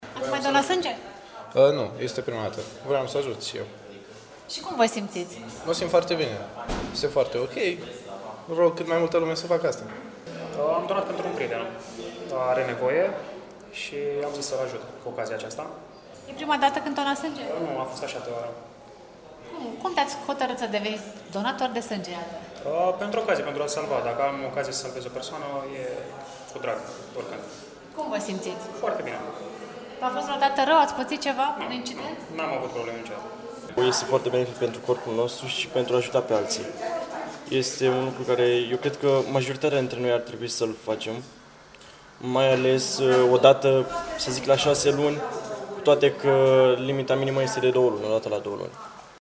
Cei prezenți la acțiunea de donare au spus că au vrut să facă o faptă bună și îi îndeamnă și pe alții să le urmeze exemplul:
14-iunie-rdj-20-vox-donatori.mp3